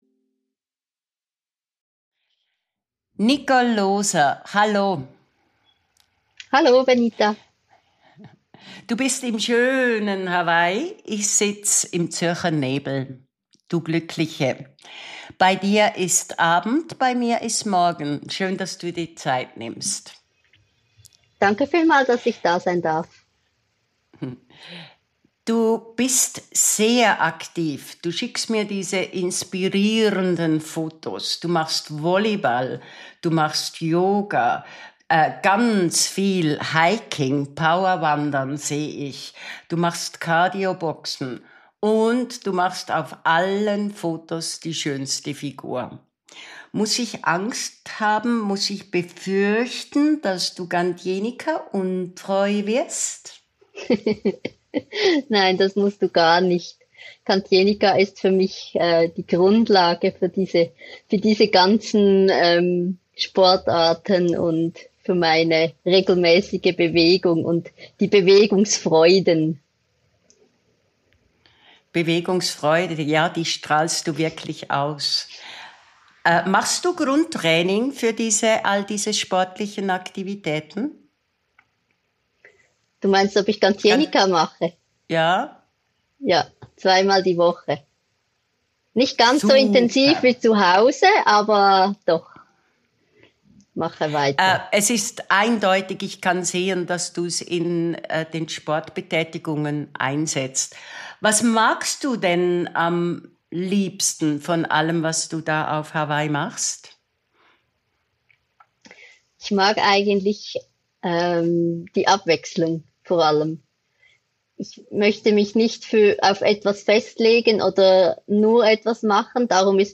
Ein Gespräch über Bewegungsfreude, innere Sicherheit und einen